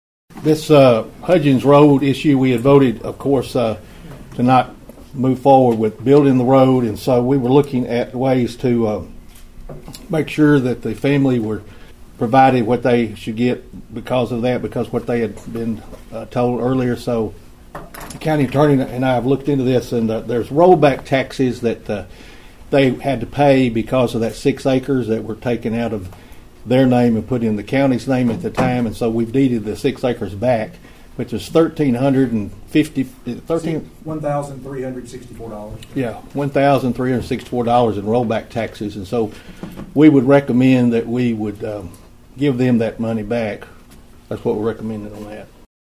During Monday’s County Commission meeting, Mayor Carr presented the settlement amount to the board members.(AUDIO)